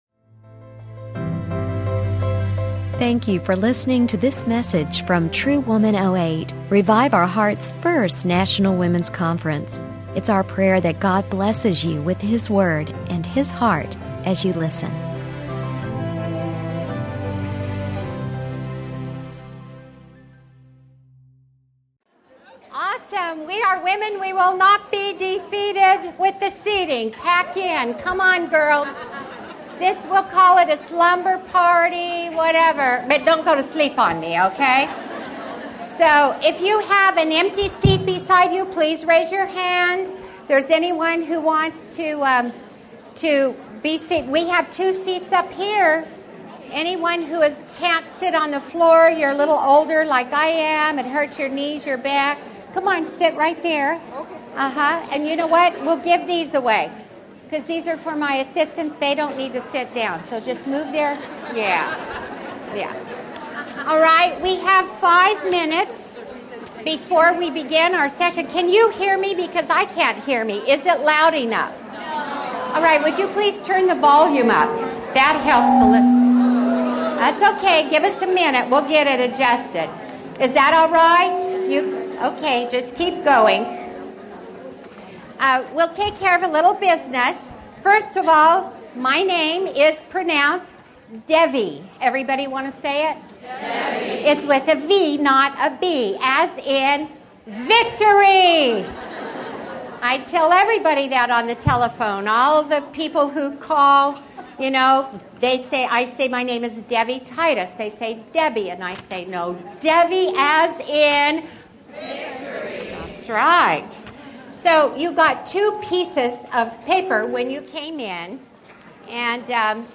Making Your Home a Place of Love and Peace | True Woman '08 | Events | Revive Our Hearts